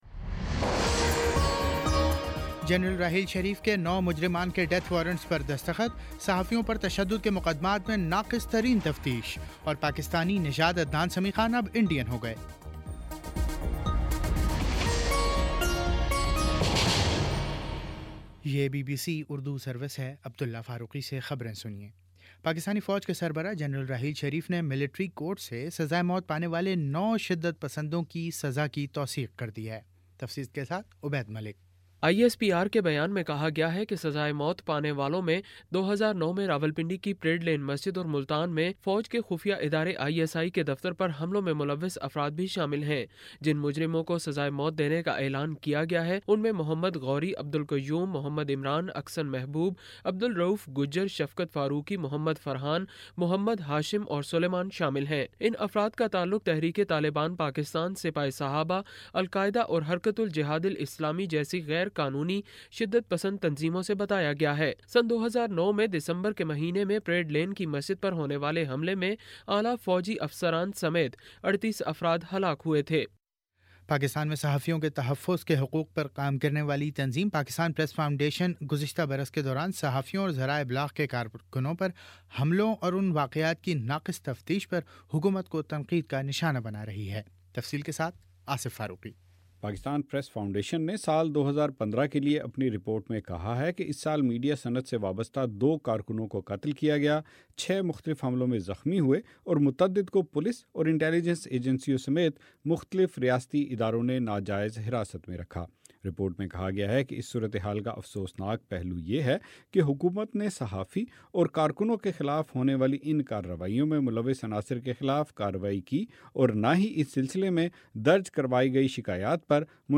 جنوری 18 : شام چھ بجے کا نیوز بُلیٹن